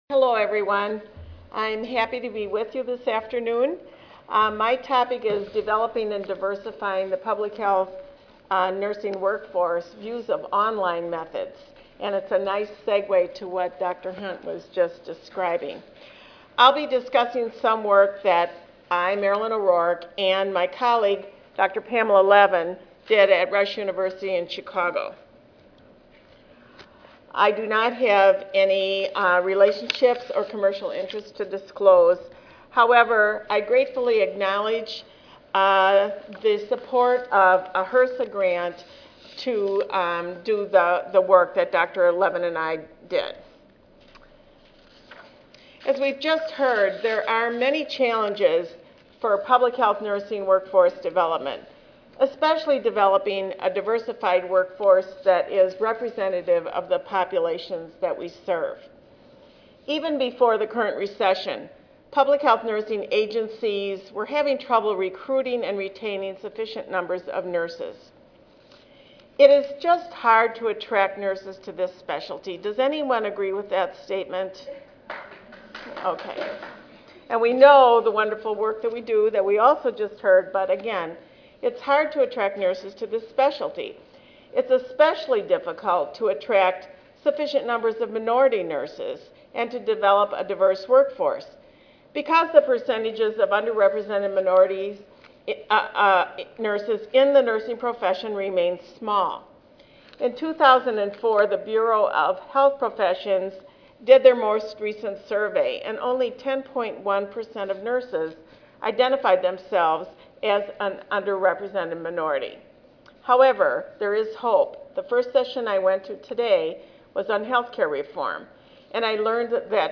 3357.0 Public Health Nursing Workforce Challenges Monday, November 9, 2009: 2:30 PM Oral This session discusses current workforce challenges for public health nurses who work in various agencies including correctional institutions.